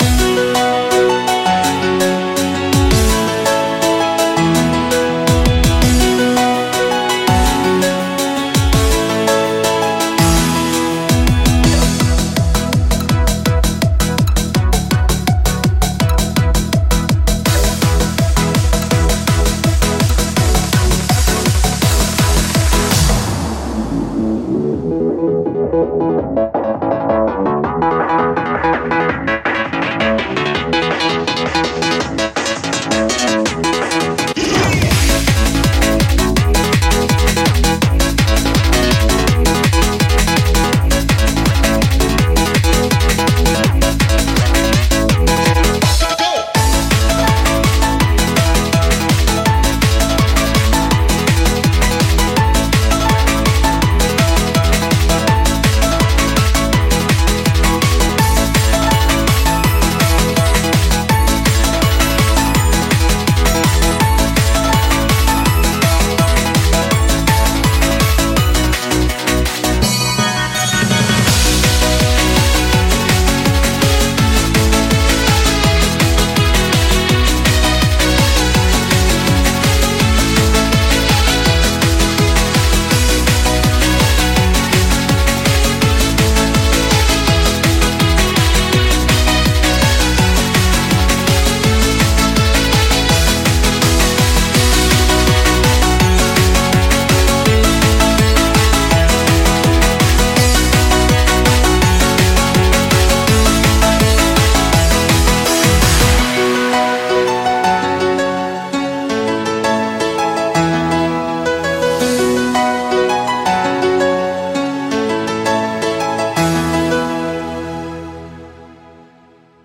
BPM165
Audio QualityPerfect (High Quality)
Comments[HAPPY NOSTALGIA]